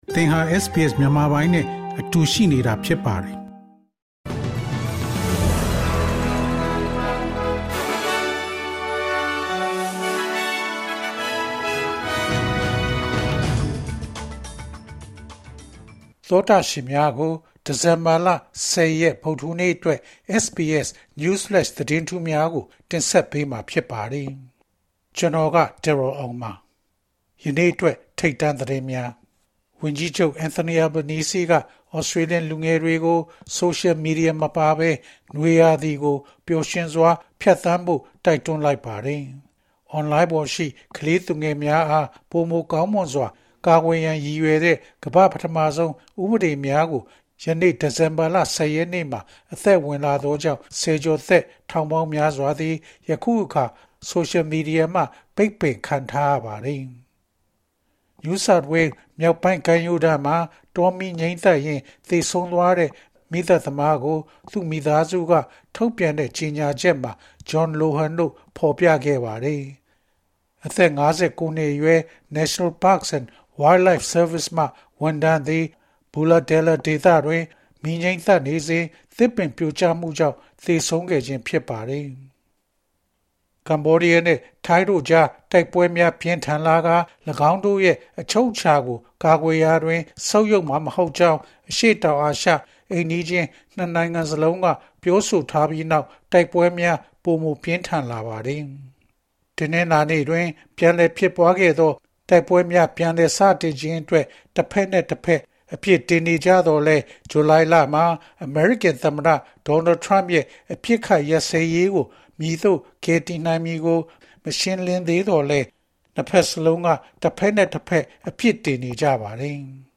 ALC: SBS မြန်မာ ၂၀၂၅ ဒီဇင်ဘာလ ၁၀ ရက် News Flash သတင်းလွှာများ။